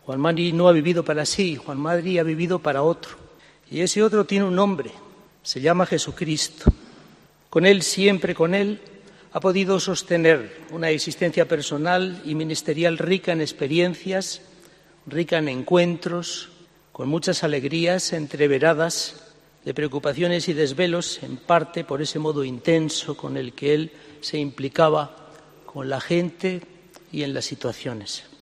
Homilía del obispo Segura en el funeral de monseñor Uriarte